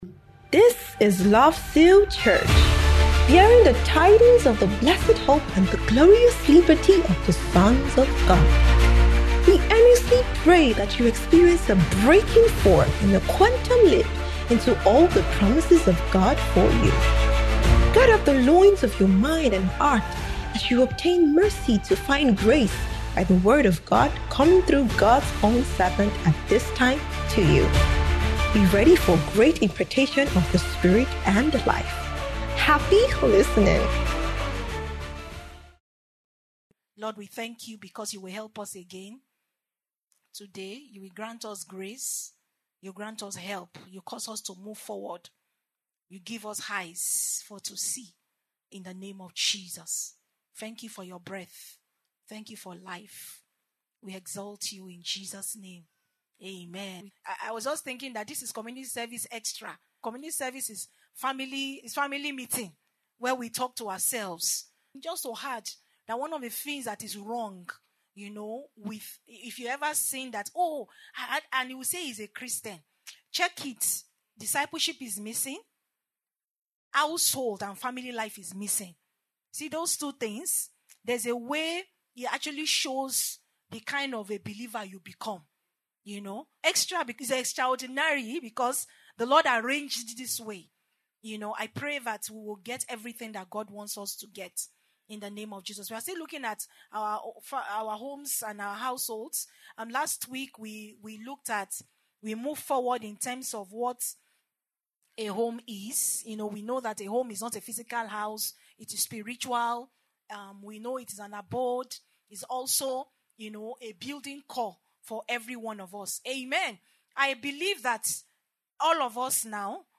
Kingdom Believers’ Community Service